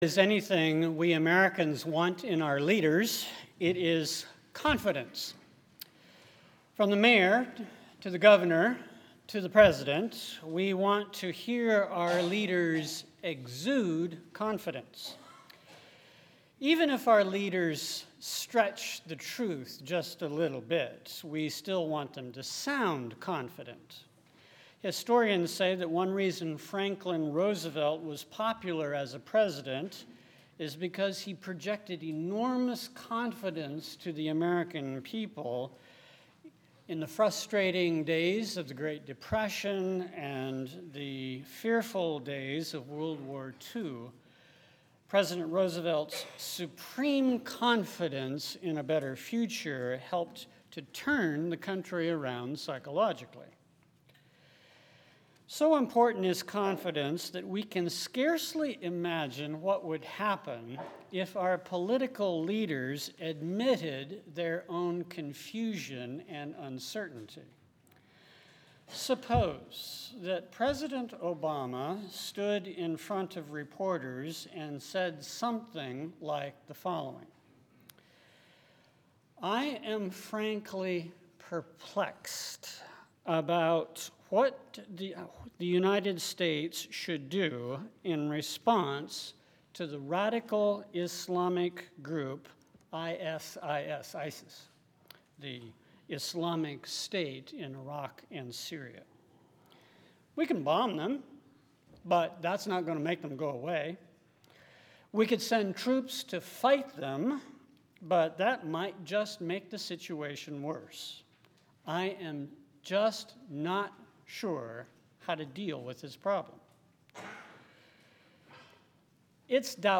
Bible References Luke 1:26 - 38 Topics confidence favor God's mission perplexed Audio (MP3) SERMON TEXT (PDF) ← A Reluctant Prophet and a Persistent God Mary, Breaker of Codes and Conventions →